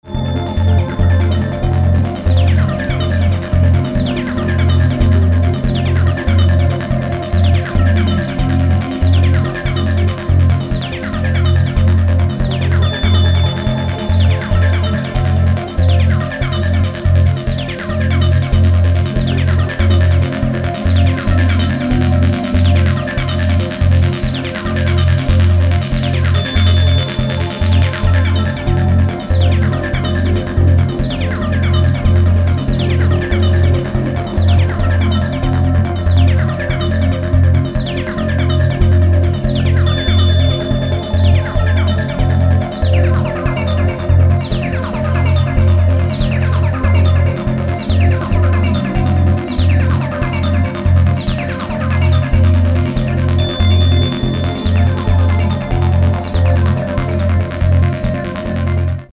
重厚にして清廉な印象が素敵です。